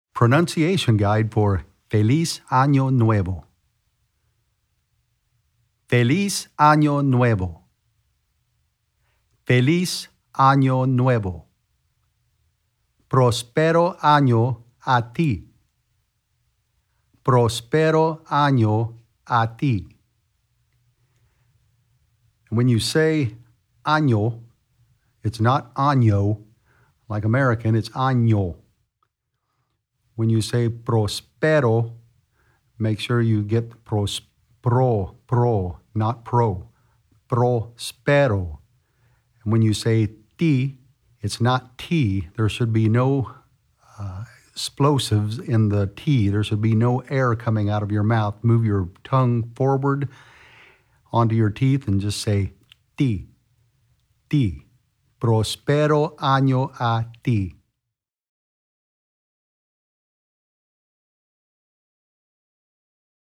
¡Feliz Año Nuevo! - Pronunciation Guide
Here's a pronunciation guide for the Spanish words in this song from Music K-8, Vol. 31, No. 2.
x312FelizAnoNuevoPronunciation.mp3